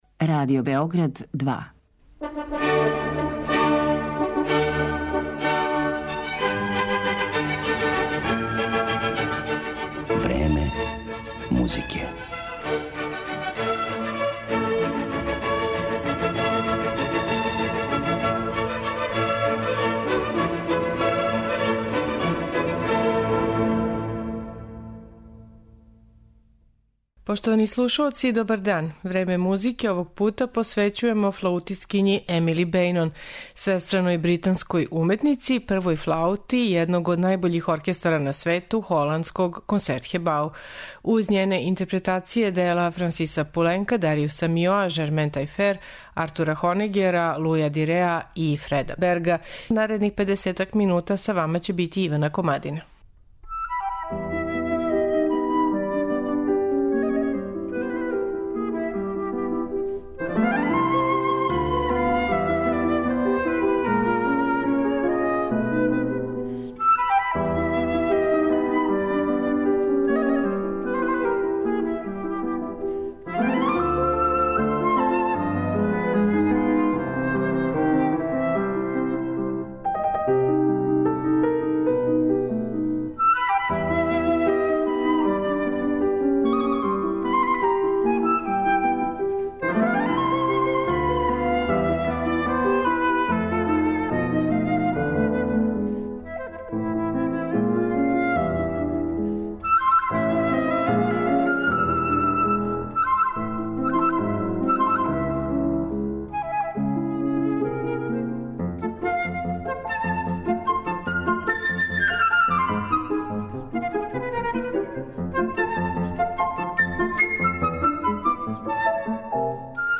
Уметнички лик ове свестране британске флаутисткиње